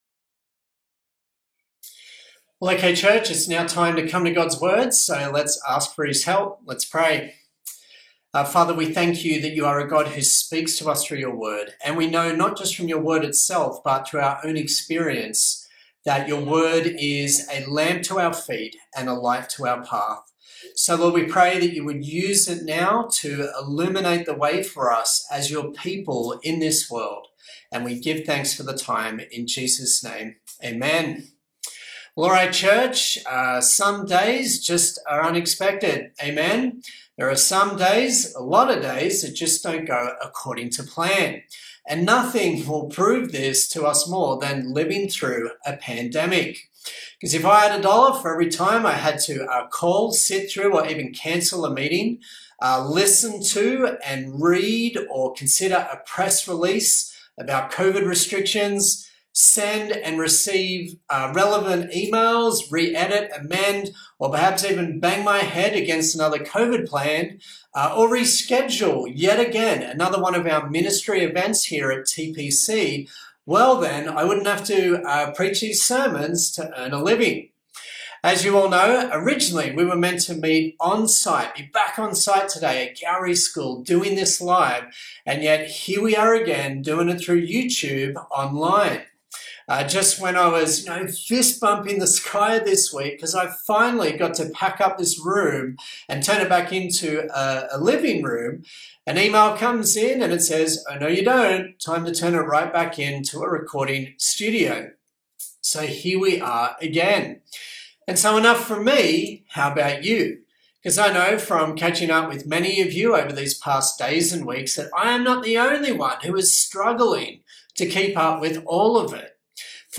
Acts Passage: Acts 15:36-16:10 Service Type: Sunday Morning